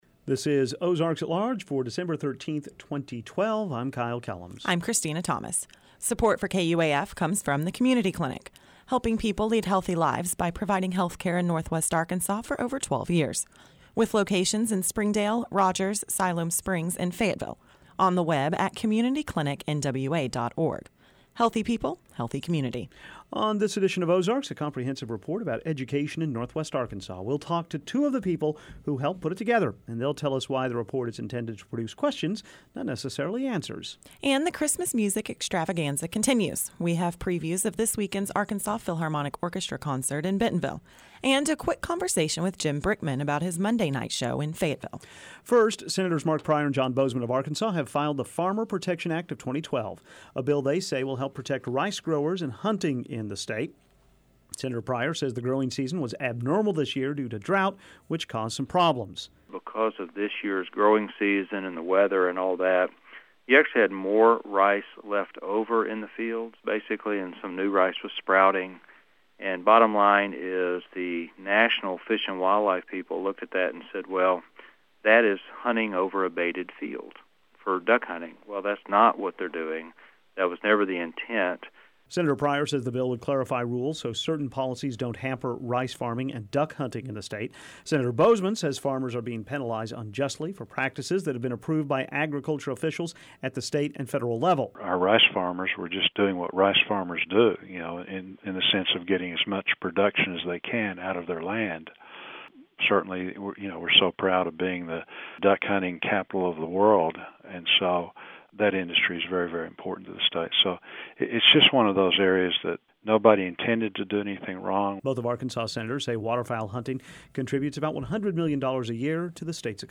Audio: oalweb121312.mp3 On this edition of Ozarks: a comprehensive report about education in Northwest Arkansas. We'll talk to two of the people who helped put it together and they'll tell us why the report is intended to produce questions and not necessarily answers.